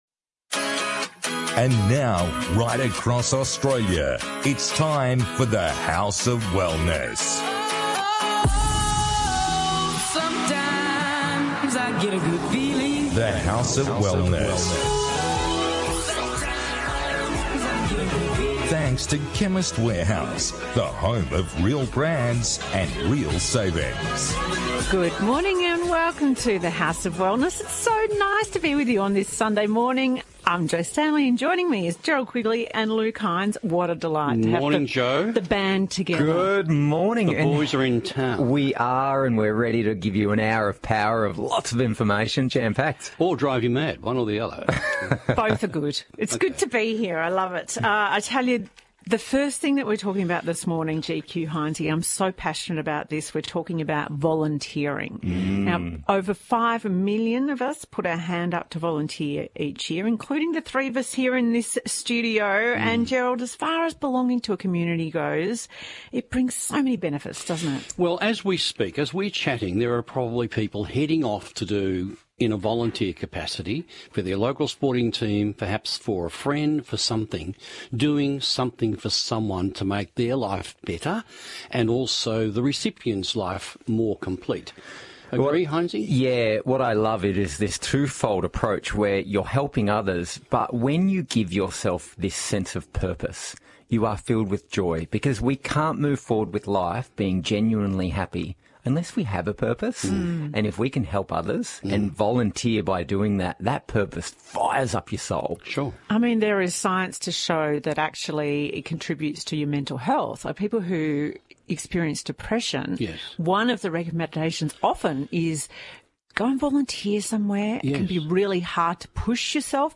This week, the team discusses the mental benefits of volunteering, making friends as an adult and more.